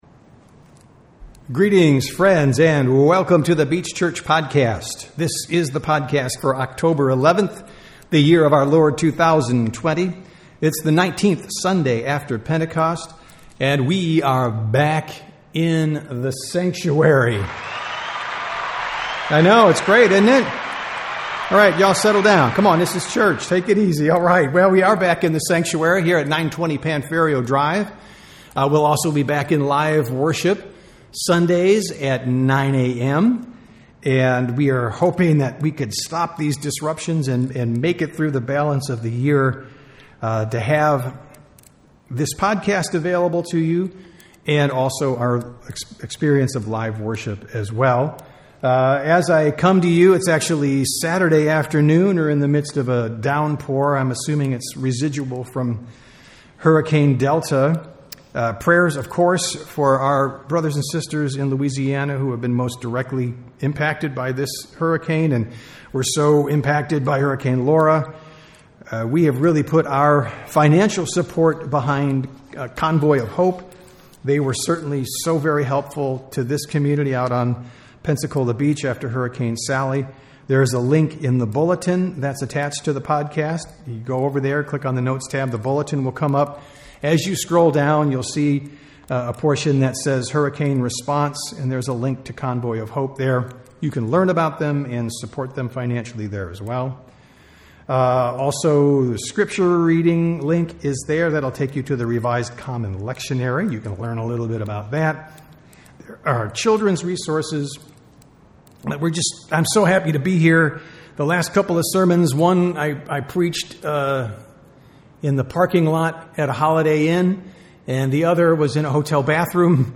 Sermons | The Beach Church